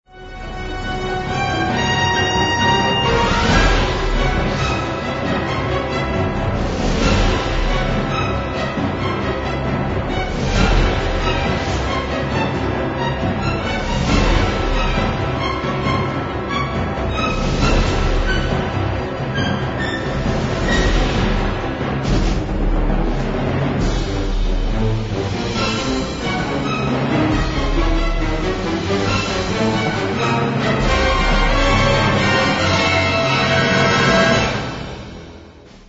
et ægte kraftværk af et symfonisk actionscore
er især domineret af blæsersektionerne